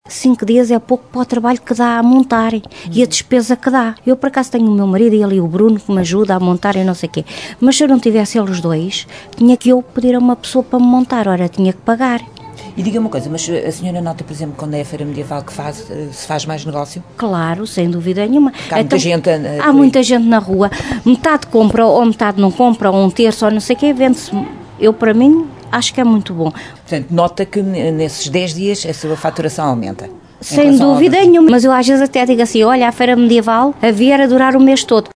A Rádio Caminha saiu à rua para ouvir comerciantes e instituições do concelho e percebeu que as opiniões dividem-se, mas a maioria dos auscultados não concorda com a decisão do presidente da Câmara.